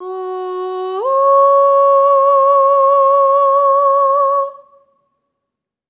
SOP5TH F#4-L.wav